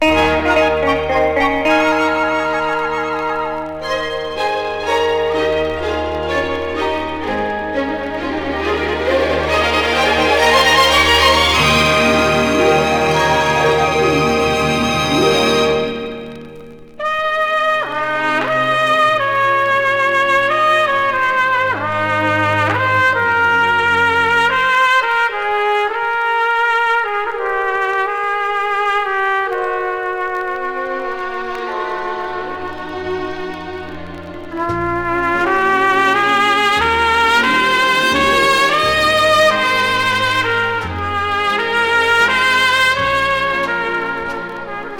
Jazz, Easy Listening　USA　12inchレコード　33rpm　Mono